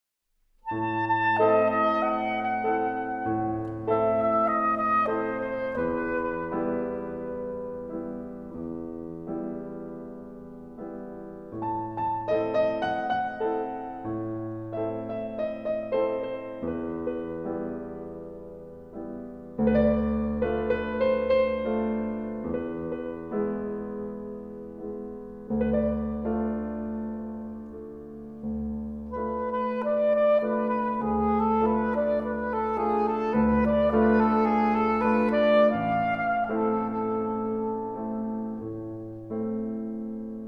Arranging Symphonic, Vocal, and Piano Works for performance on Carillon
This translated to a contemplative musical style.
I left the melody in the middle.